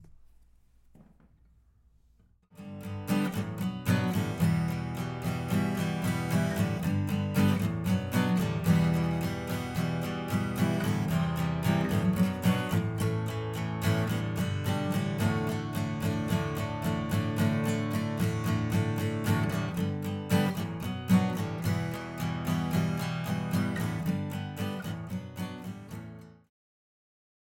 Help with mic'ing/recording acoustic guitar
Experiment # 5 New take - no padded box for the mic. I placed the mic on a table level with the acoustic guitar and parallel to the neck. Front of mic facing directly toward guitar. This take is completely dry and I have not added any FX afterward. I did normalize and added a couple of fades...
I used a different strum (down-strokes mostly, and not so much on the E & B strings). I think it's a cleaner sound from the guitar, with some warmer tones.